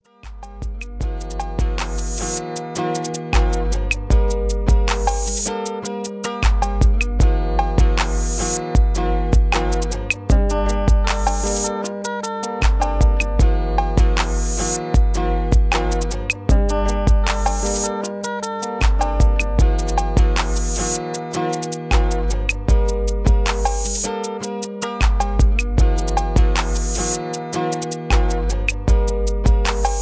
R&B guitar medley.